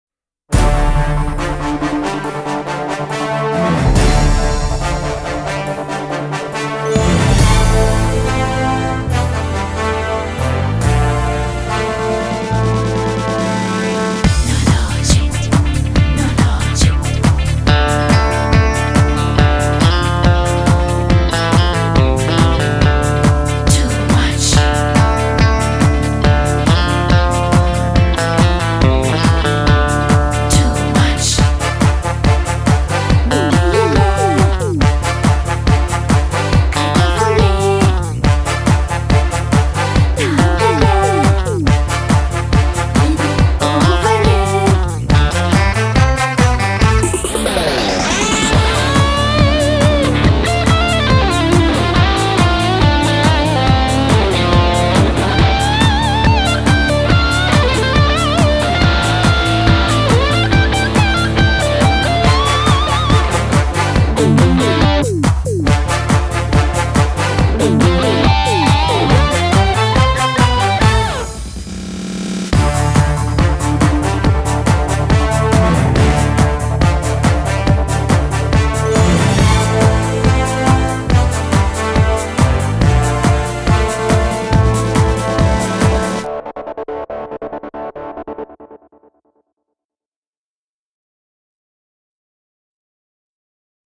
Theme Music
Singer: Instrumental